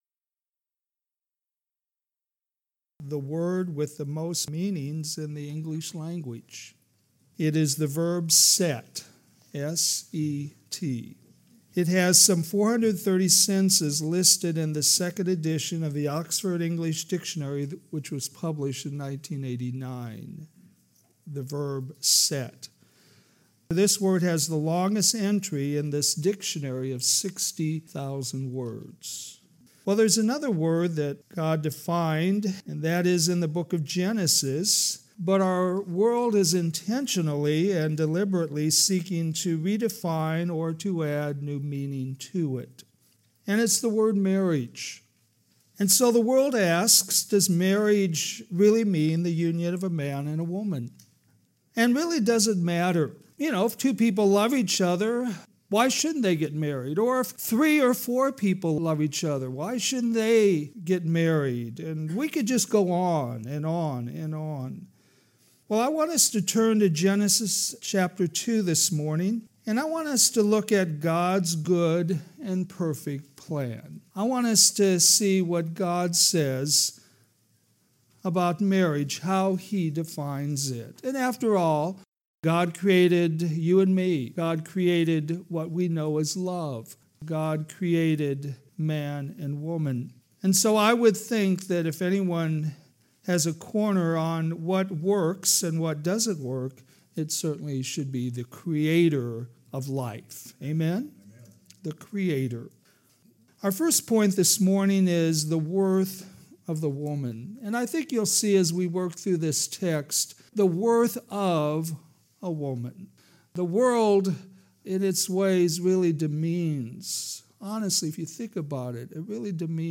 All sermons available in mp3 format